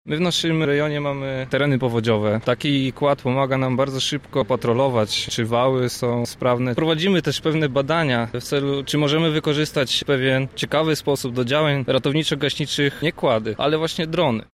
To wszystko było można zobaczyć dziś na placu zamkowym w Lublinie.